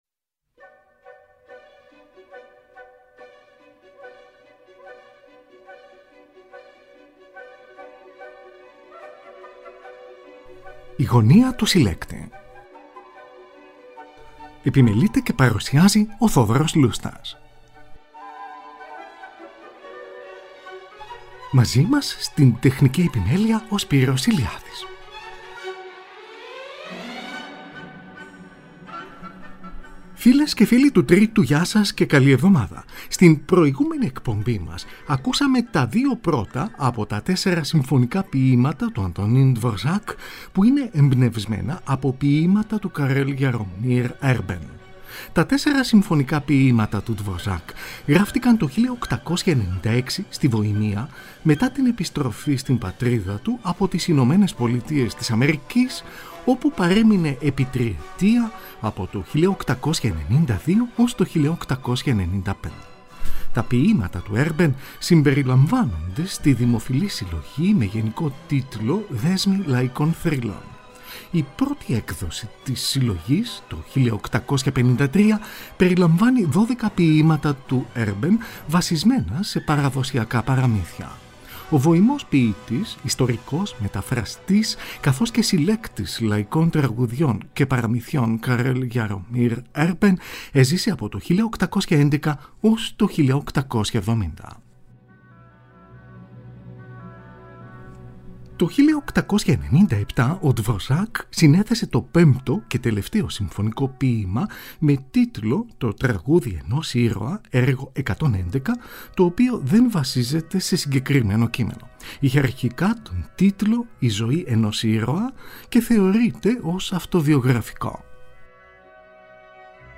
ΤΕΣΣΕΡΑ ΣΥΜΦΩΝΙΚΑ ΠΟΙΗΜΑΤΑ ΤΟΥ ANTONĺN DVOŘÁK EΜΠΝΕΥΣΜΕΝΑ ΑΠΟ ΠΟΙΗΜΑΤΑ ΤΟΥ ΚΑREL JAROMĺR ERBEN (ΠΡΩΤΟ ΜΕΡΟΣ)